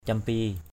/cam-pi:/ (d.) đàn champi, đàn tranh = harpe à 16 cordes.